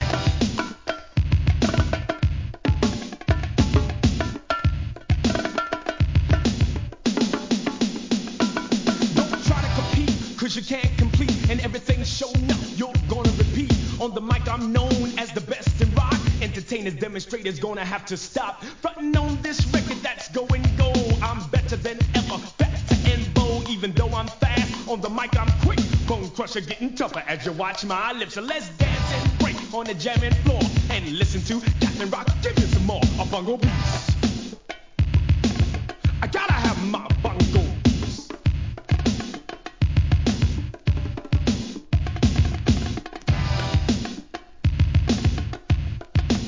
HIP HOP/R&B
エレクトロHIP HOPコンピレーション